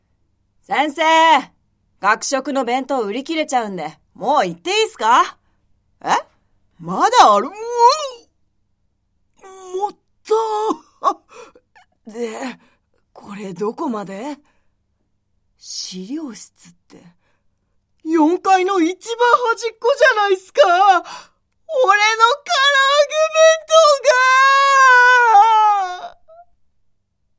ボイスサンプル
セリフ3